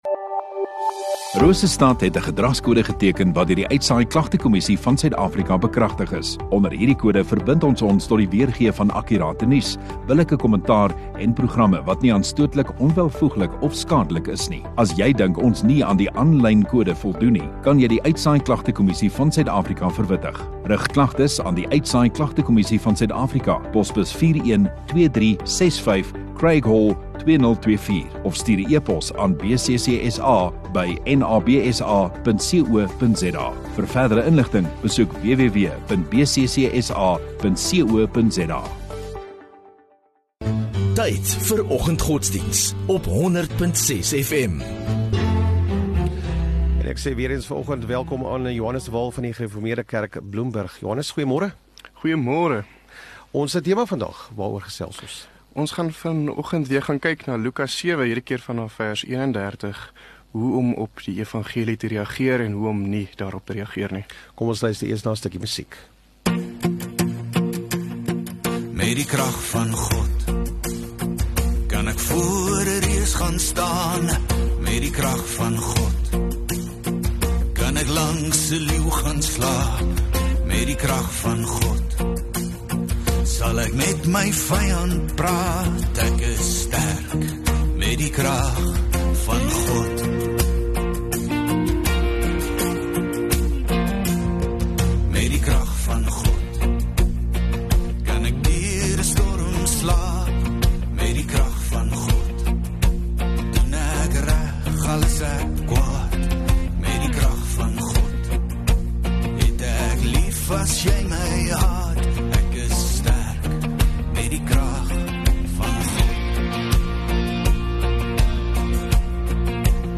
31 Jul Woensdag Oggenddiens